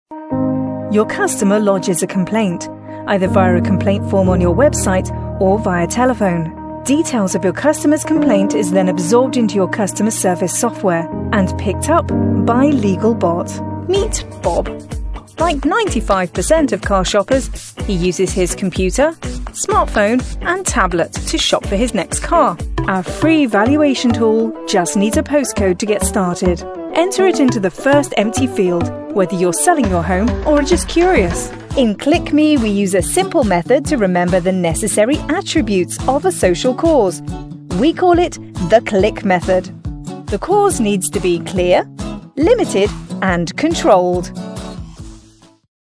女英120 英式英语美式英语女声 elearning 略成熟 大气浑厚磁性|沉稳|娓娓道来|科技感|积极向上|时尚活力|神秘性感|调性走心|亲切甜美|感人煽情|素人
女英120 英式英语美式英语女声 大气浑厚磁性|沉稳|娓娓道来|科技感|积极向上|时尚活力|神秘性感|调性走心|亲切甜美|感人煽情|素人